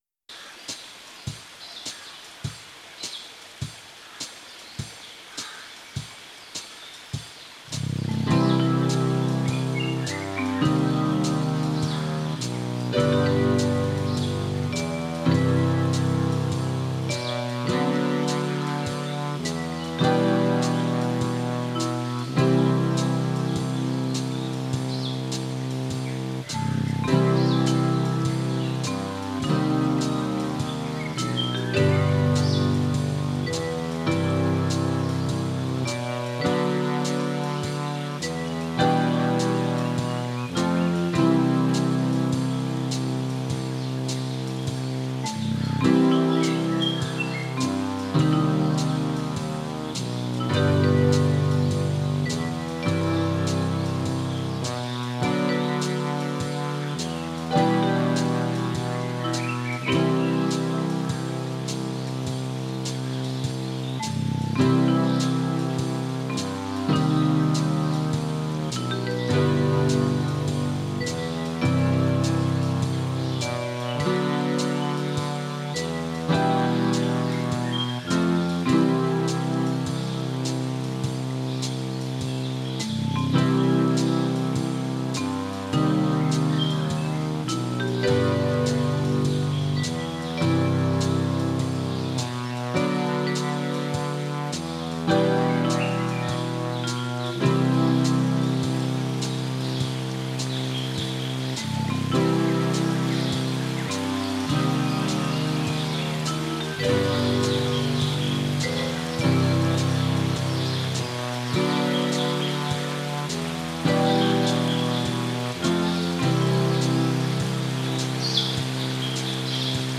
Experimental Performance